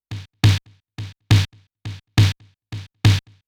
BOOM SD   -R.wav